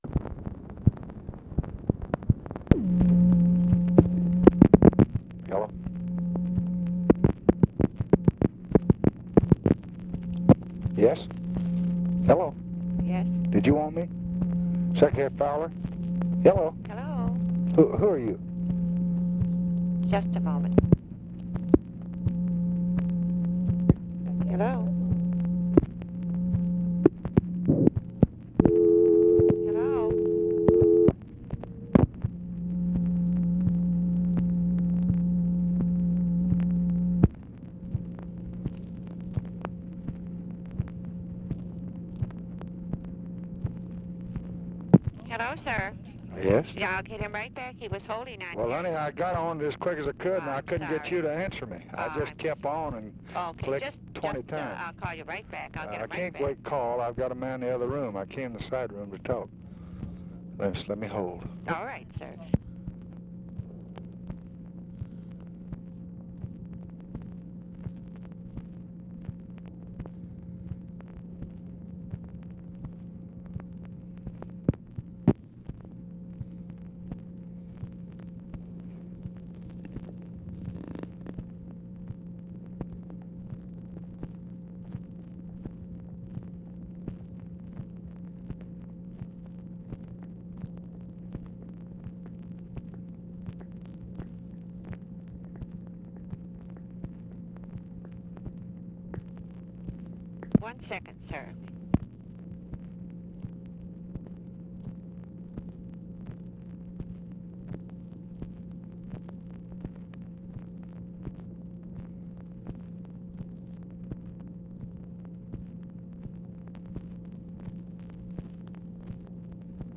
Conversation with HENRY FOWLER and TELEPHONE OPERATORS, February 4, 1966
Secret White House Tapes